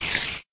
spider